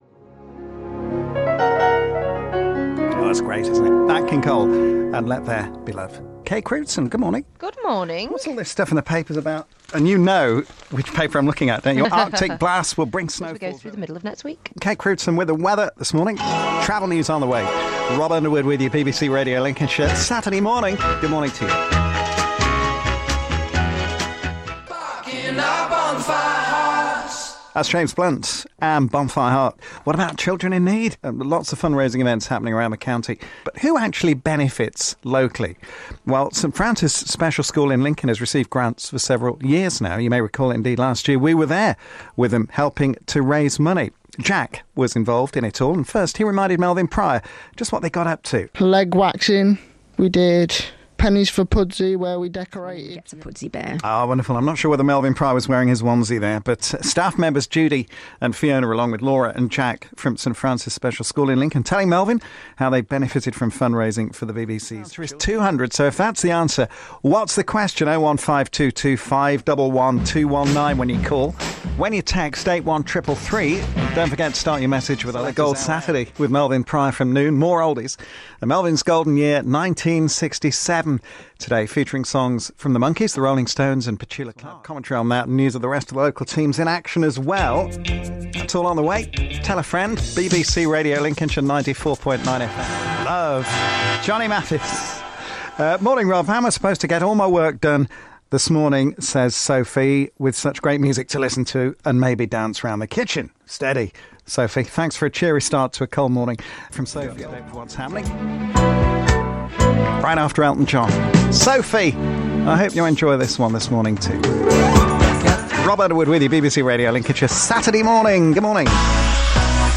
radio montage